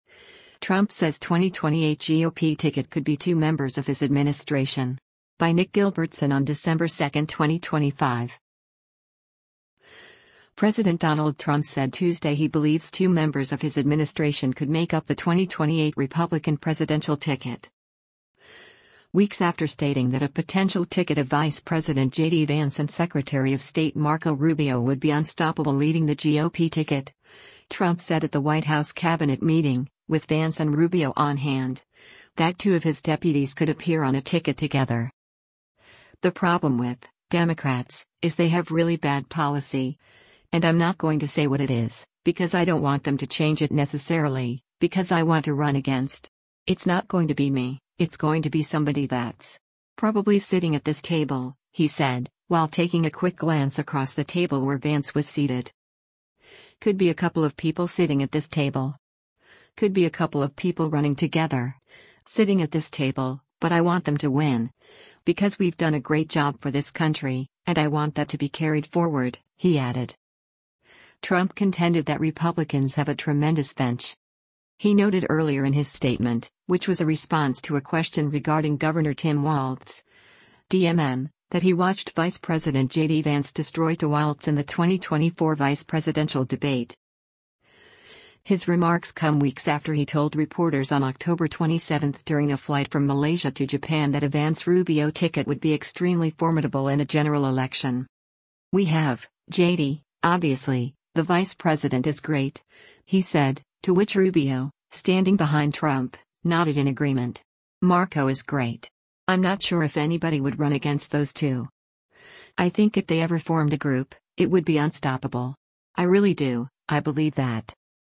Weeks after stating that a potential ticket of Vice President JD Vance and Secretary of State Marco Rubio would be “unstoppable” leading the GOP ticket, Trump said at the White House cabinet meeting, with Vance and Rubio on hand, that two of his deputies could appear on a ticket together.